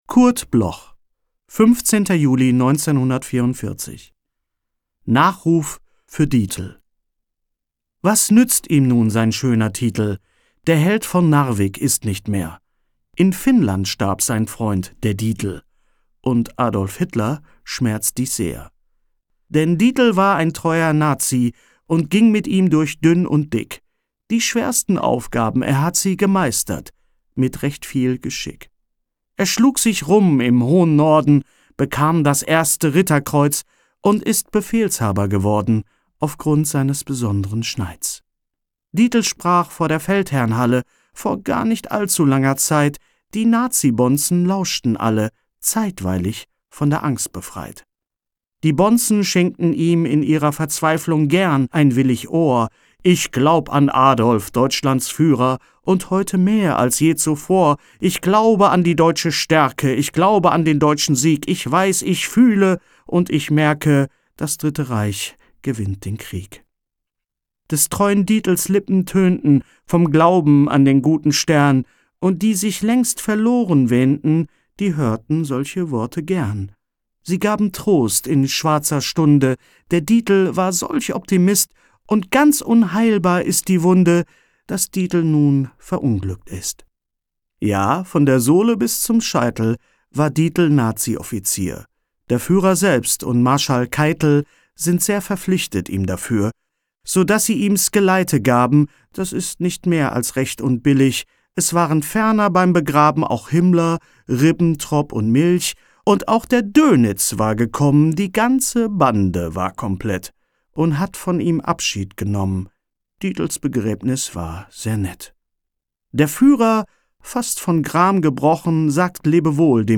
vorgetragen von Hennes Bender
Hennes-Bender-Nachruf-fuer-Dietl.m4a